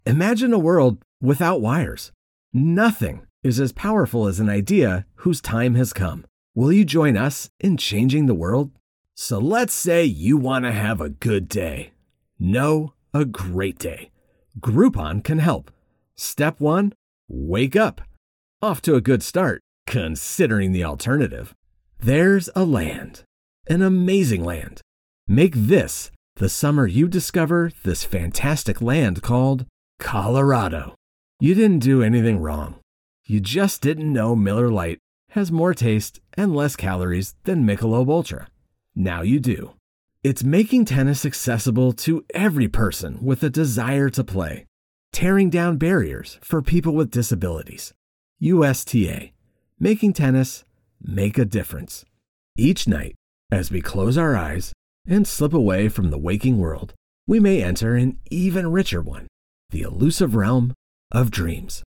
Male
English (North American)
Adult (30-50)
Main Demo
Commercial/Documentary
Studio Quality Sample
Words that describe my voice are Warm, Rich, Laid-back.